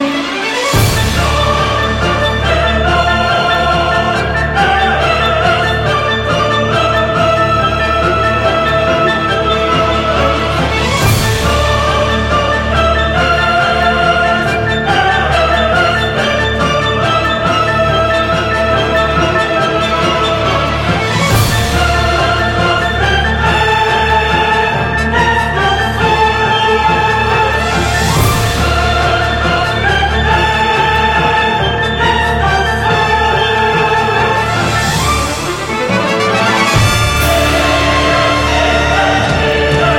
из сериалов
японские
эпичные